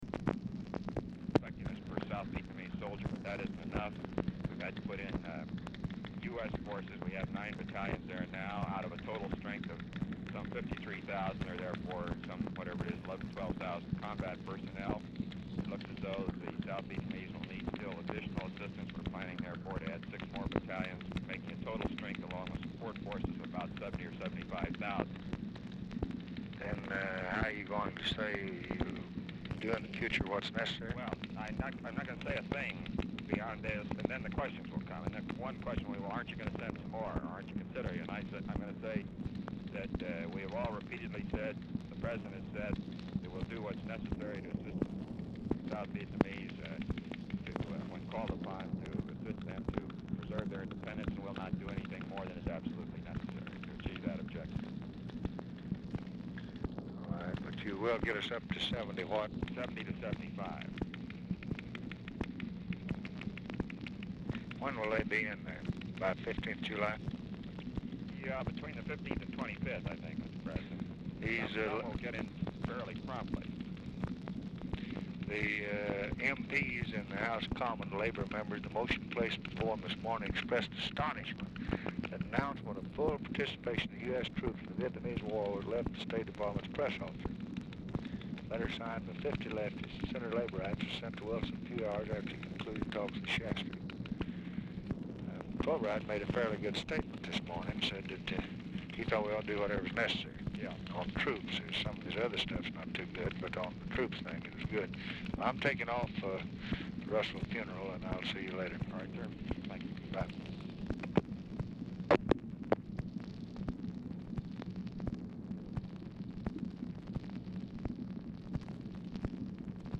Telephone conversation
RECORDING STARTS AFTER CONVERSATION HAS BEGUN; POOR SOUND QUALITY; TICKER TAPE MACHINE AUDIBLE IN BACKGROUND